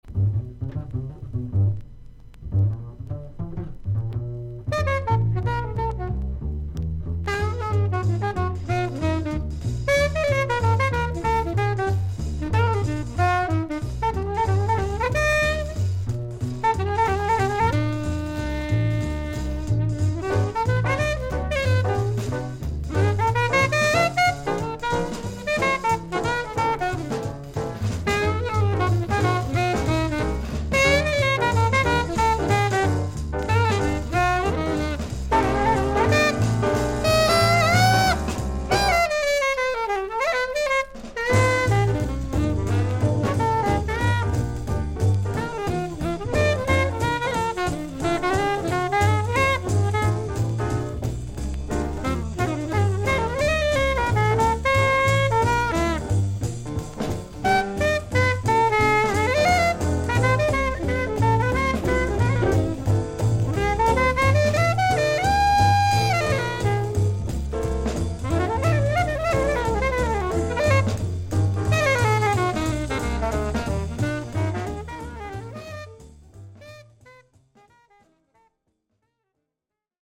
少々軽いパチノイズの箇所あり。少々サーフィス・ノイズあり。クリアな音です。
ジャズ・サックス奏者。